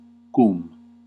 Ääntäminen
US : IPA : /wɪð/